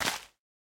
Minecraft Version Minecraft Version snapshot Latest Release | Latest Snapshot snapshot / assets / minecraft / sounds / block / big_dripleaf / tilt_down4.ogg Compare With Compare With Latest Release | Latest Snapshot
tilt_down4.ogg